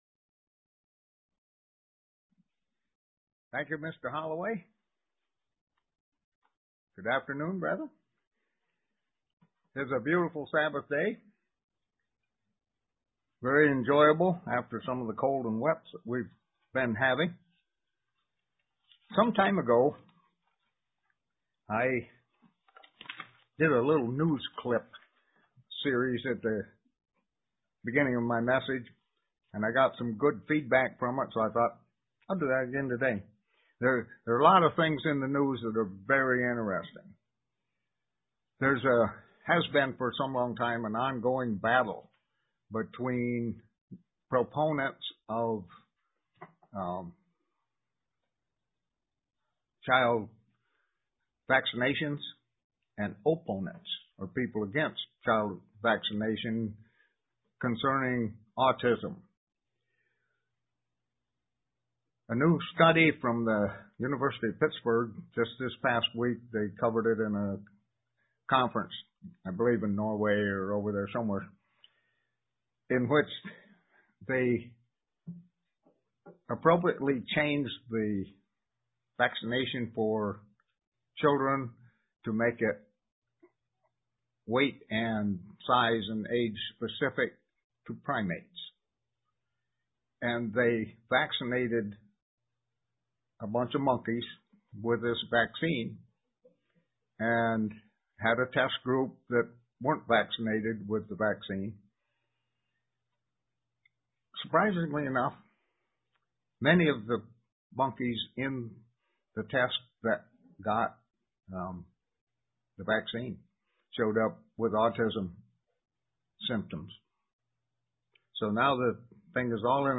Print Lessons we can learn from some of the women in the Bible UCG Sermon Studying the bible?
Given in Elmira, NY